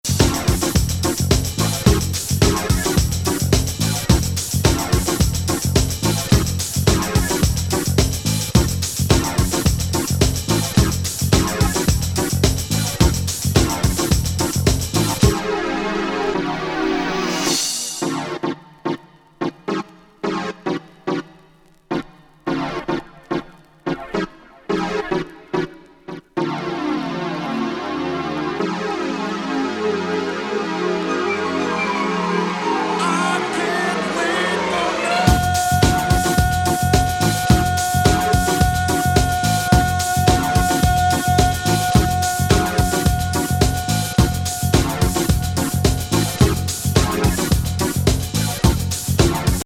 ユーロ感あるイタロ・ハウス古典。
緩めのBPMが心地よくグランドビート調のリズムとばっちりはまってます。